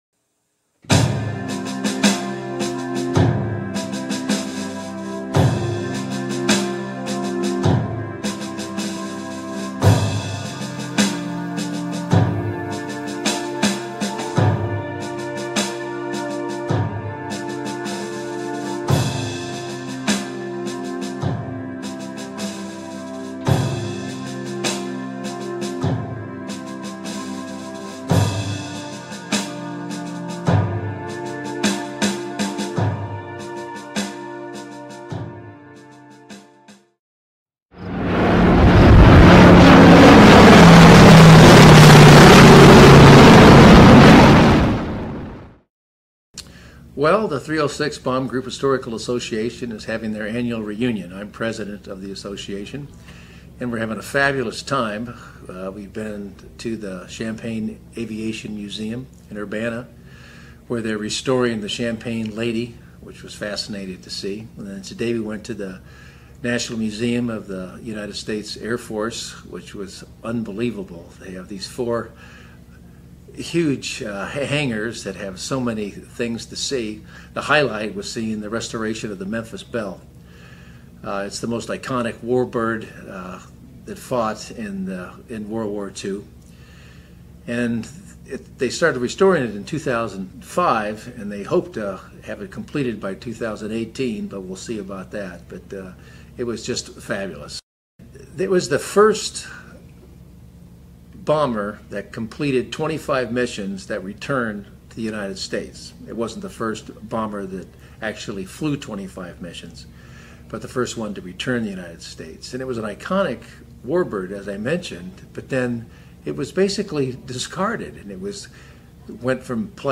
Restoration of B-17 Memphis Belle & Interview